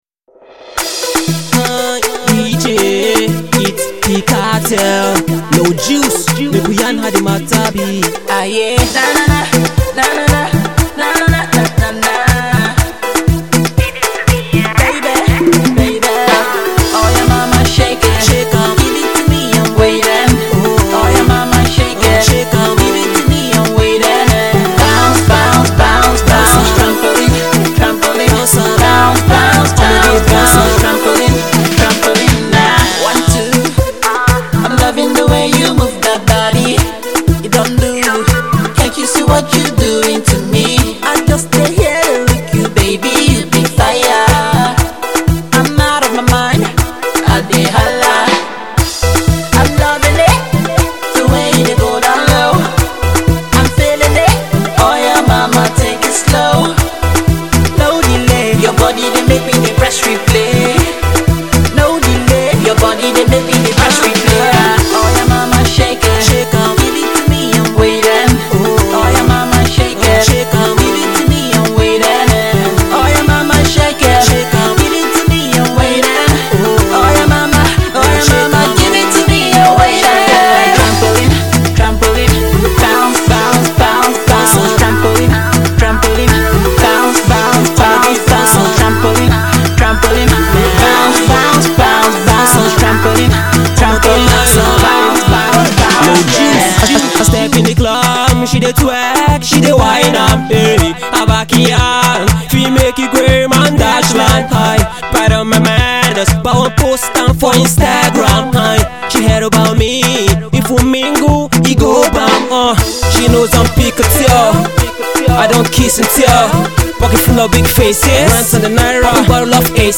Pop …
Nigerian Artiste
Its actually a Good Pop Tune